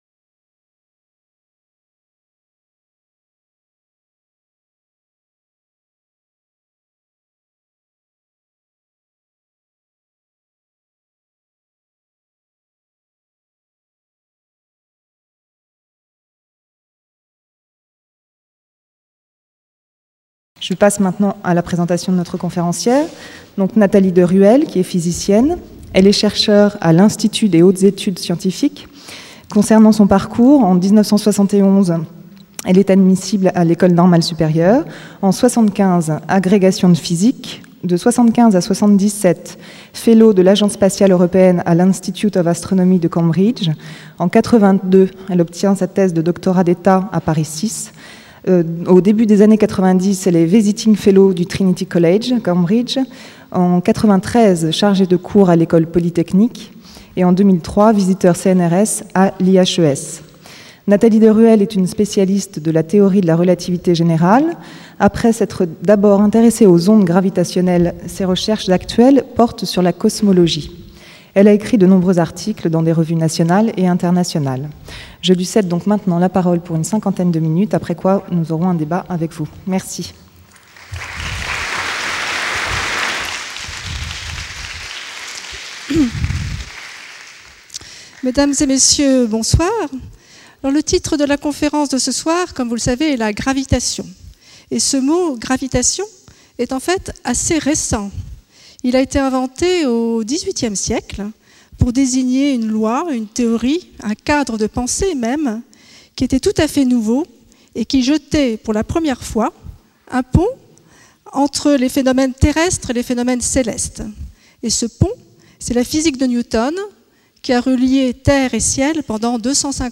La conférence du 21/06/05 en audio